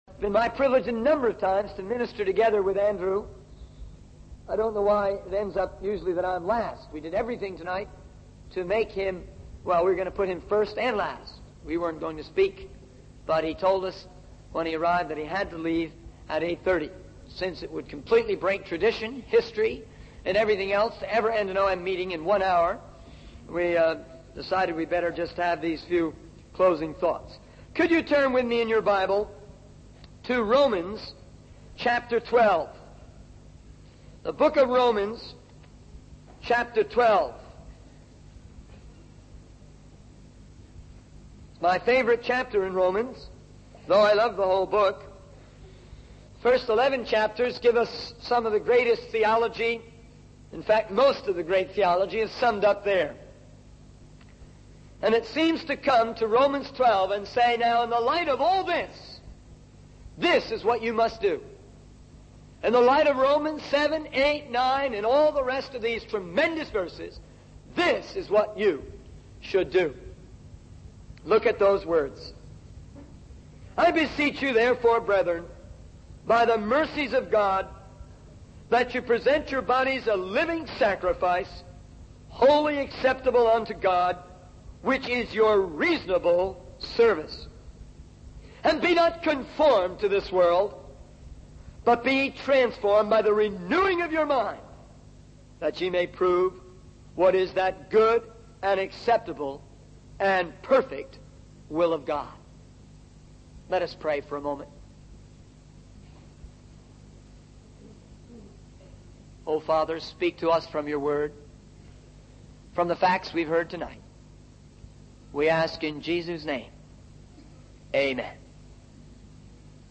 In this sermon, the speaker highlights the urgent need for spreading the Gospel in East Pakistan, where 90% of the population has never heard the Gospel.